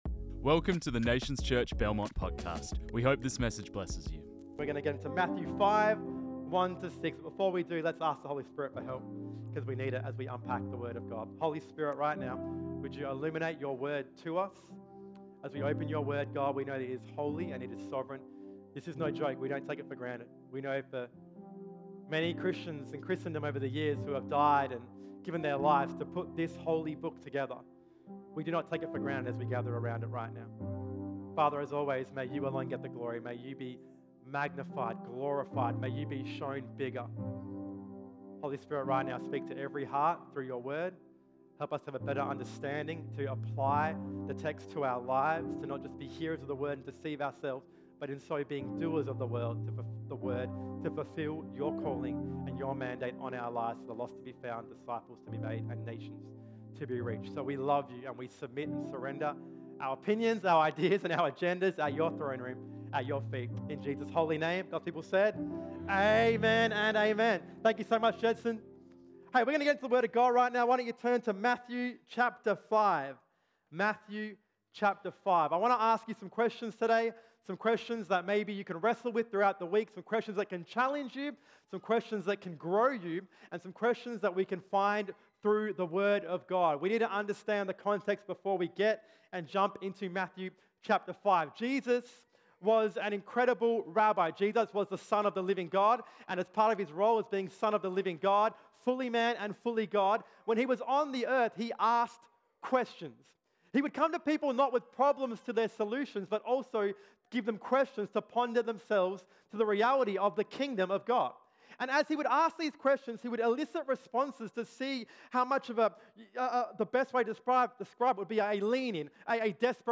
This message was preached on 17 September 2023.